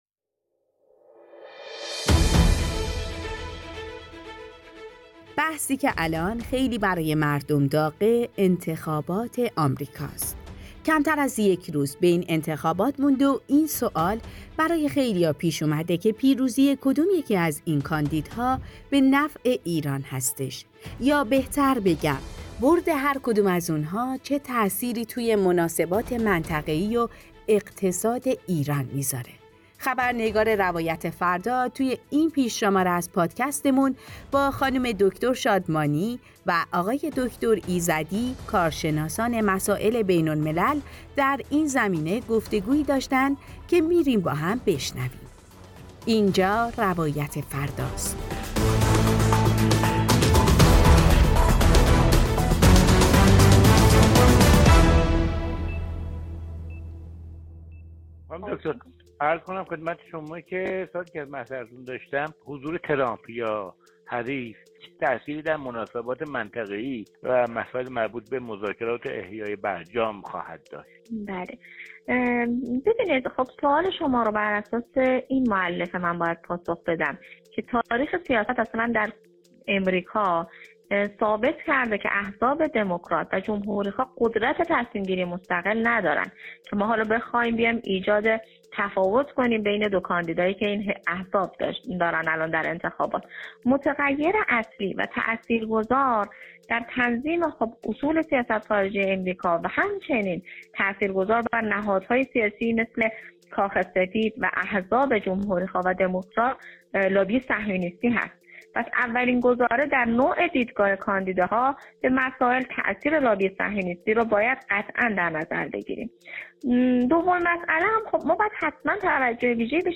گفتگو «روایت فردا» با کارشناسان بین‌الملل